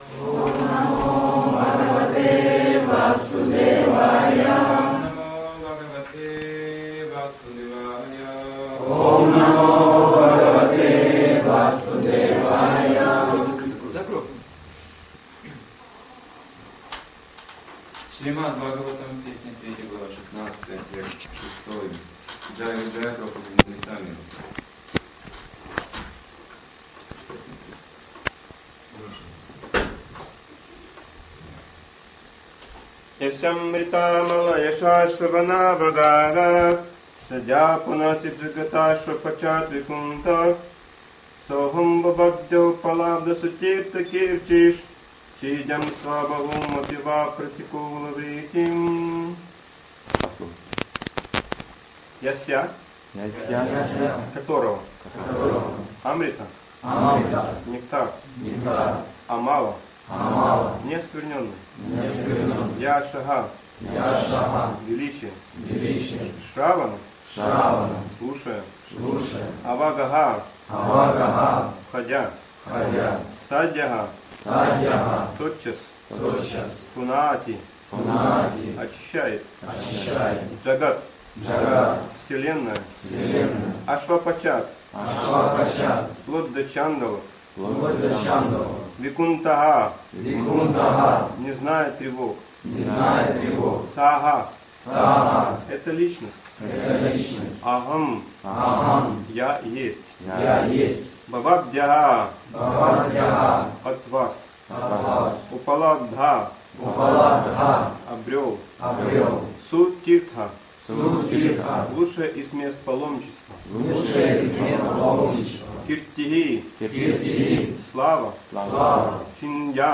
Алчевск